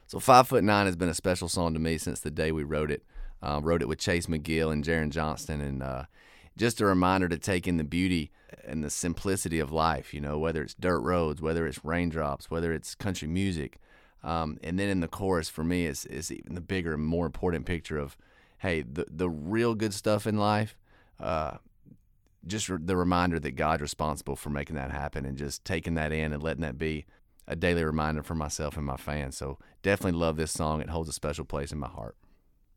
Audio / Tyler Hubbard talks about his new single, "5 Foot 9."